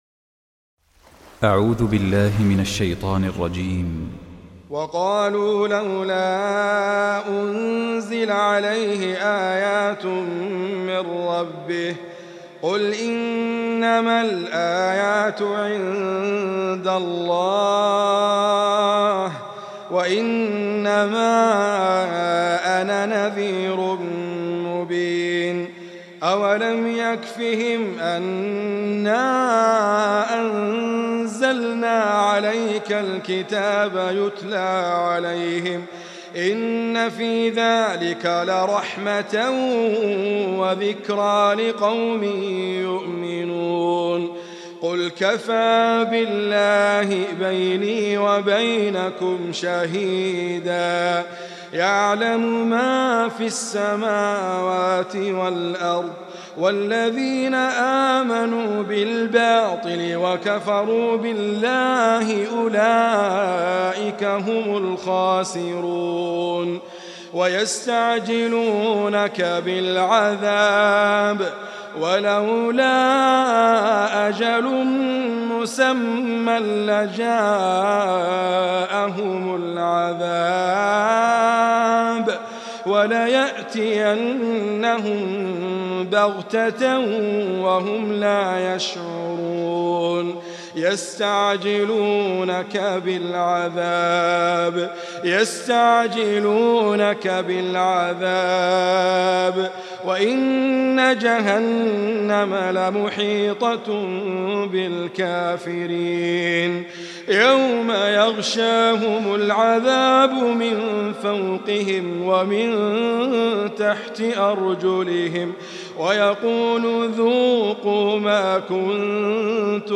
Recitime
Idriss Abkar